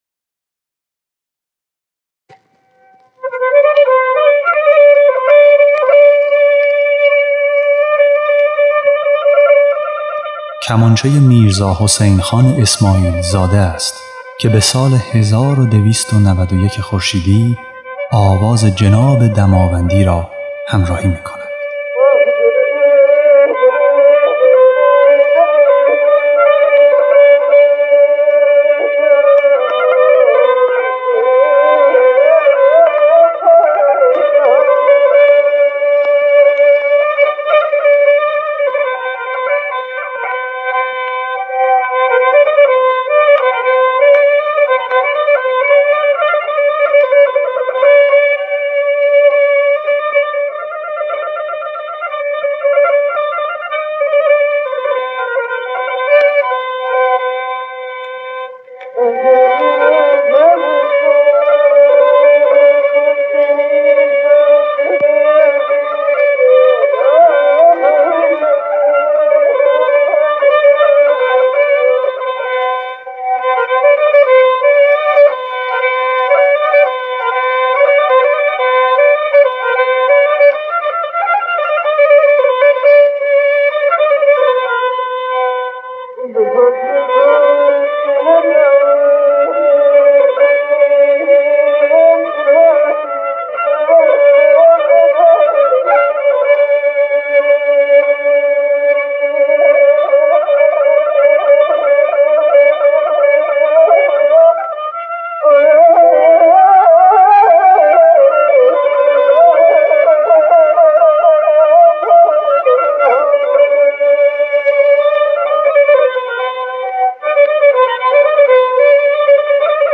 خواننده
نوازنده کمانچه